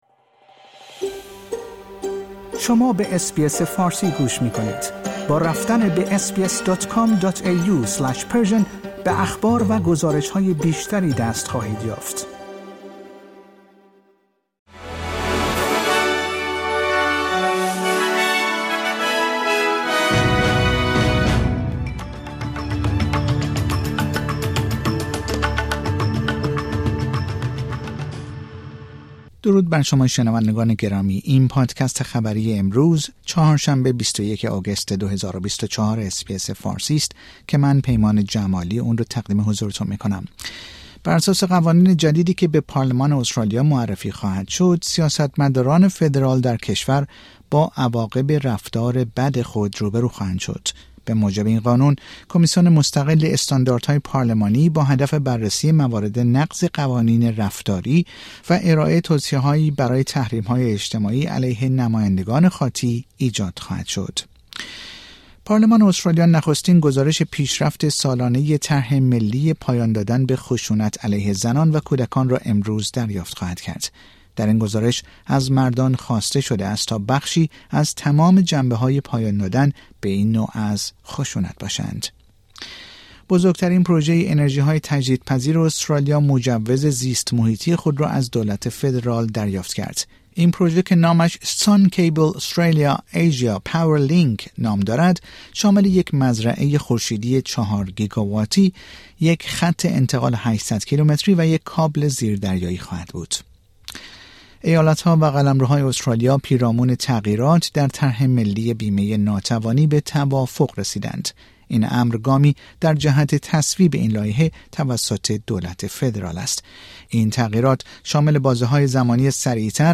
در این پادکست خبری مهمترین اخبار استرالیا در روز چهارشنبه ۲۱ آگوست ۲۰۲۴ ارائه شده است.